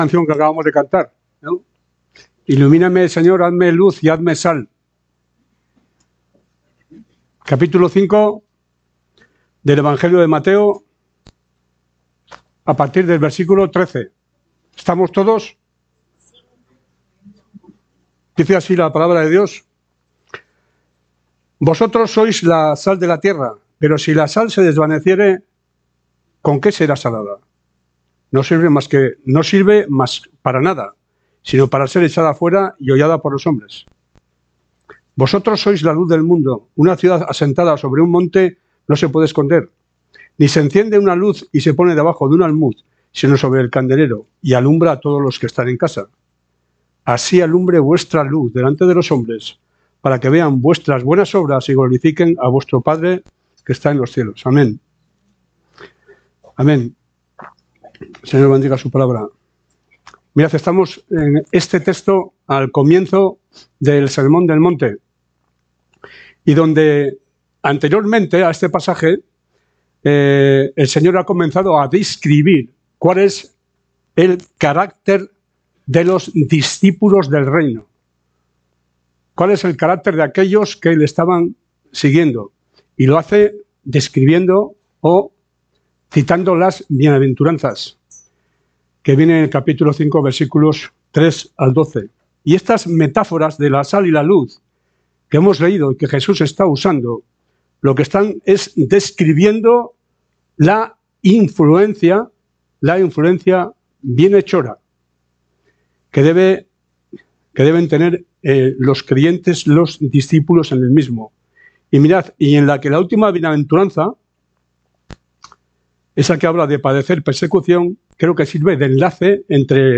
Predicación